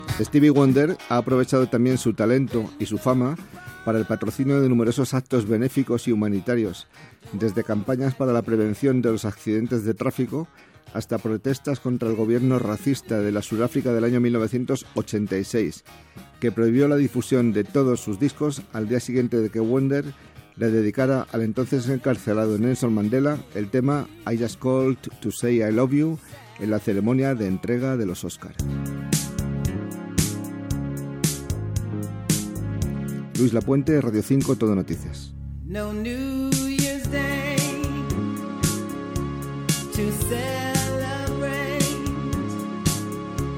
Indicatiu del programa, informació sobre el cantant Stevie Wonder.
Musical